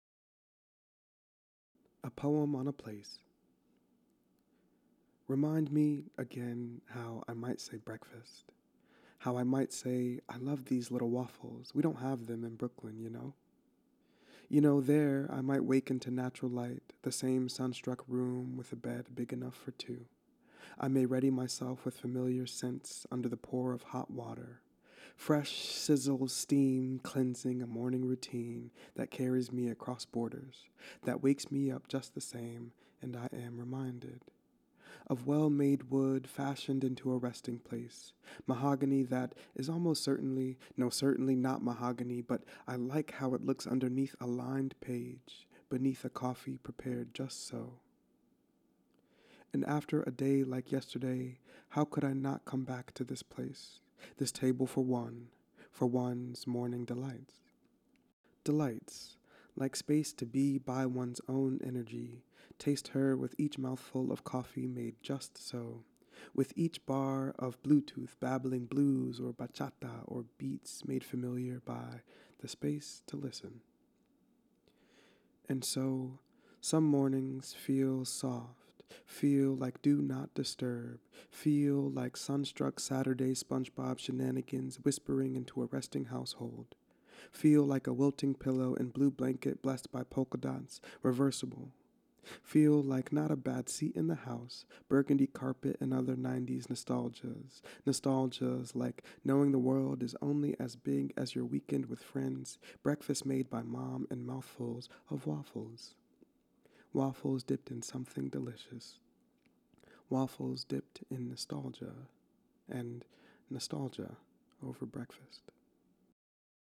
reading a poem on place